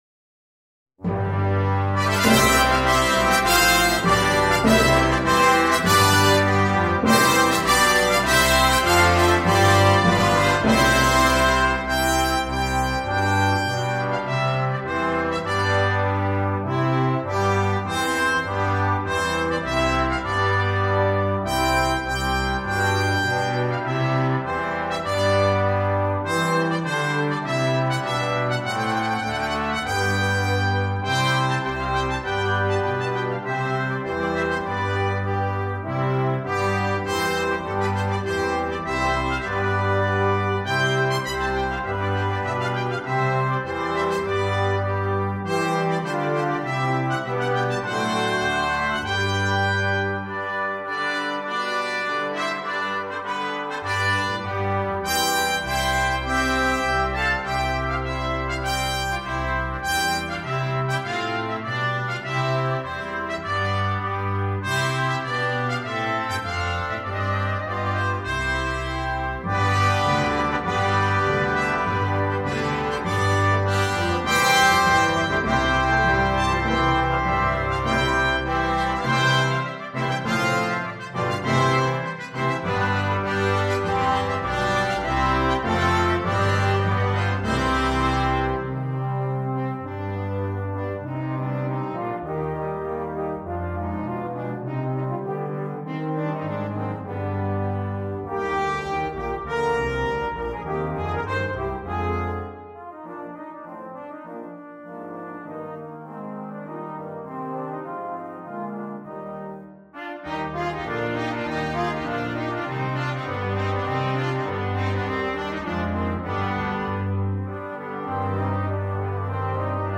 2. Brass Ensemble
10 brass players
with solo instrument, Duet
C Trumpet (Duet)
Classical
Percussion